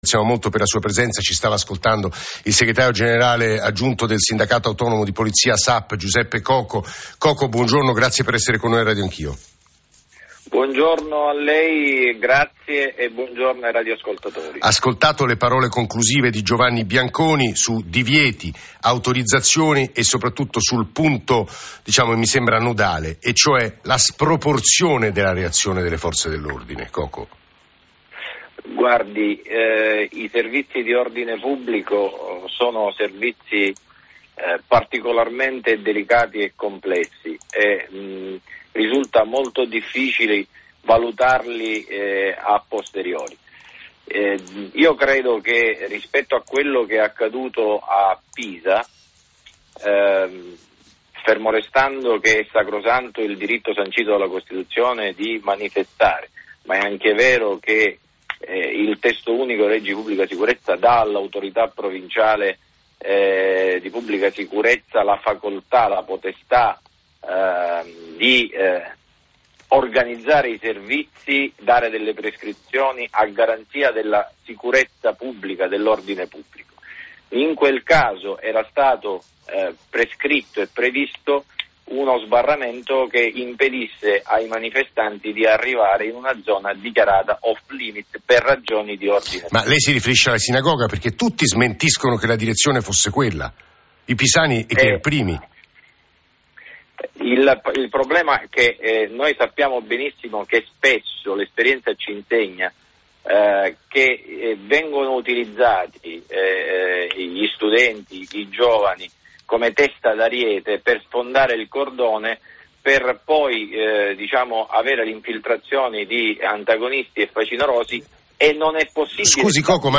ai microfoni di ‘Radio anch’io’, su Rai Radio 1